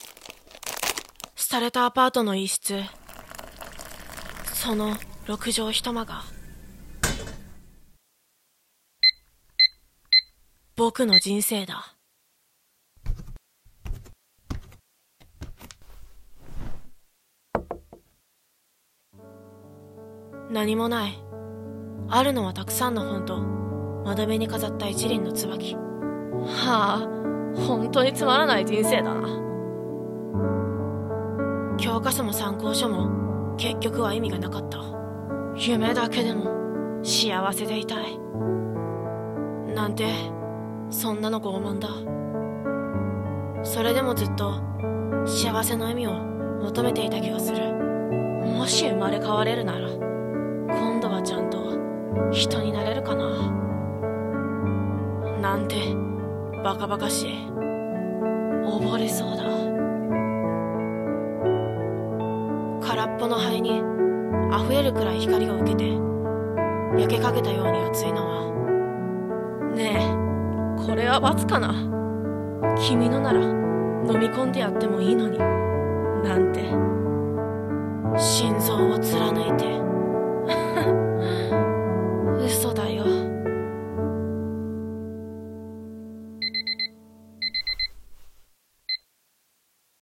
【1人声劇】